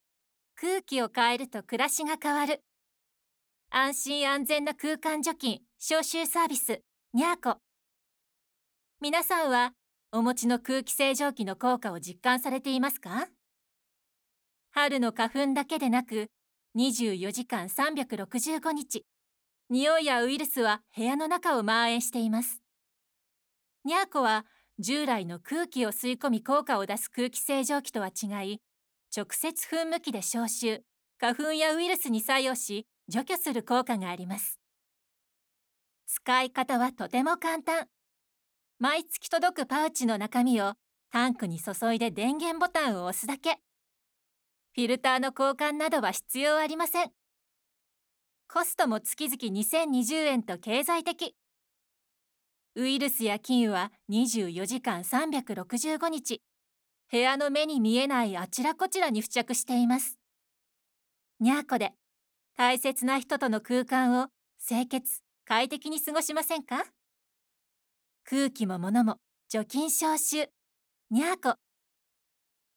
【ボイスサンプル：ナレーション】 現